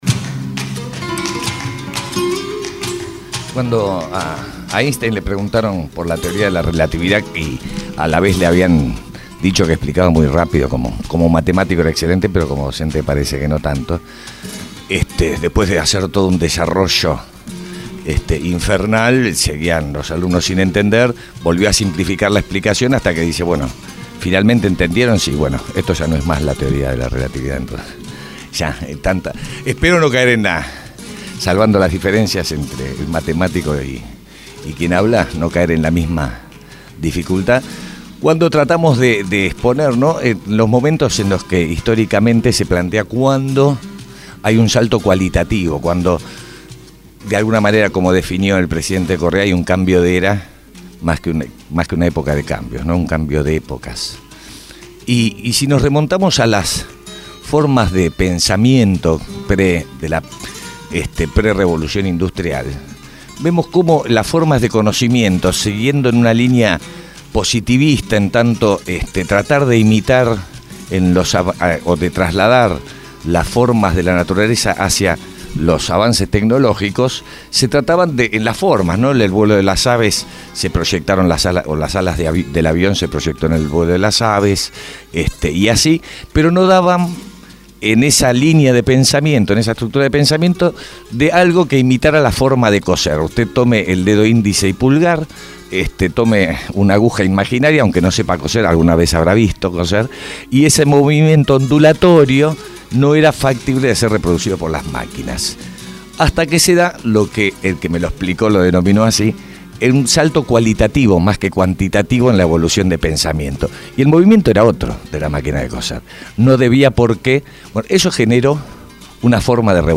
EDITORIAL